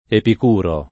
Epicuro [ epik 2 ro ]